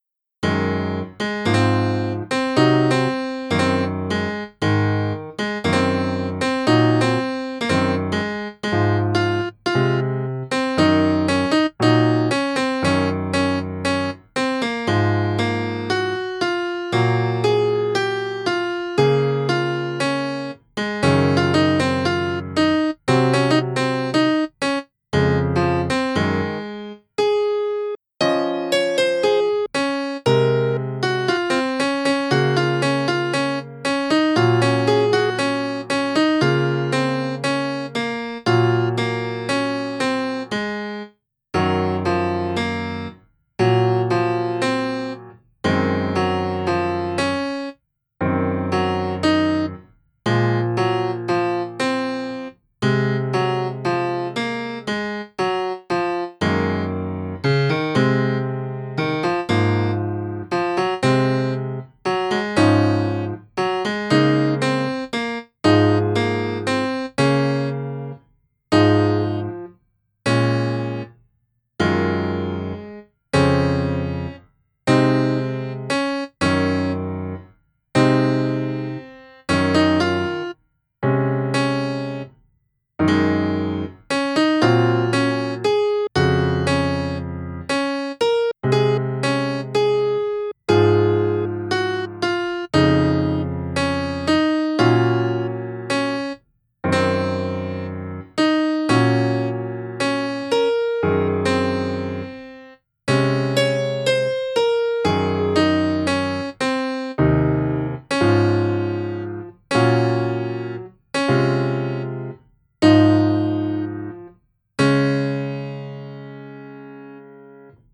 BGM
ジャズ暗い